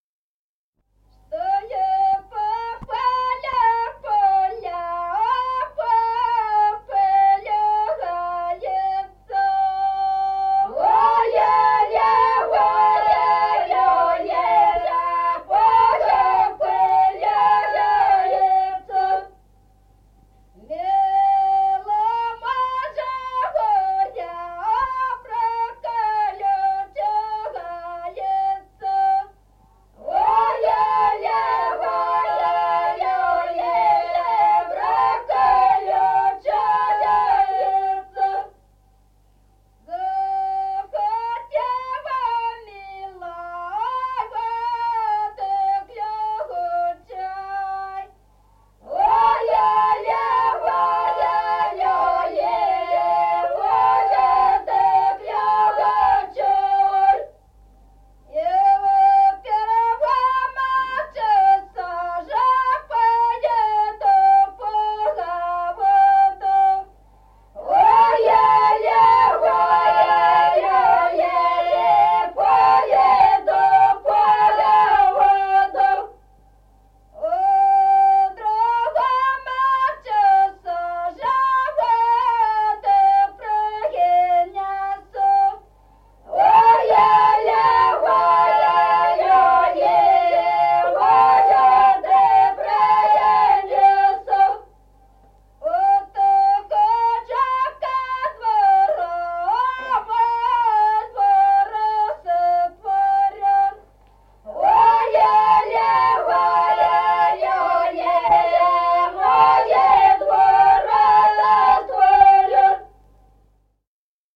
Песни села Остроглядово. Чтой по полю.